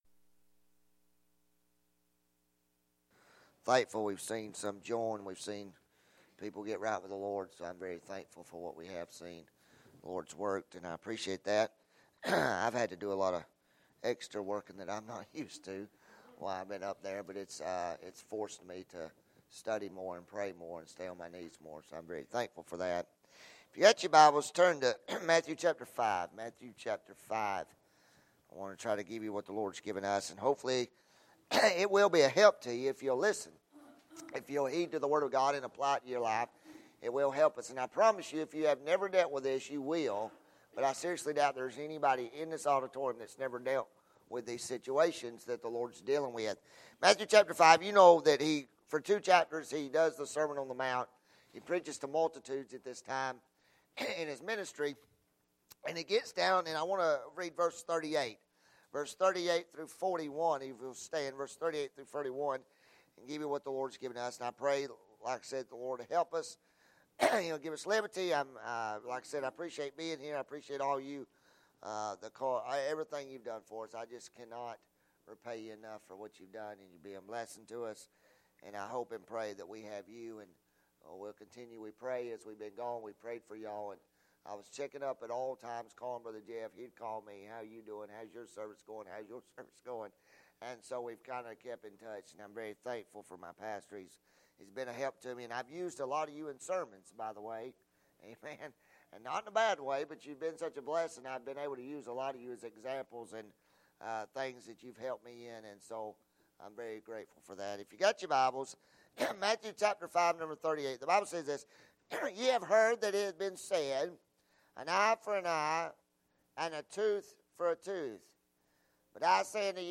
Welcome Grove Baptist Church, Marietta GA
Sermon media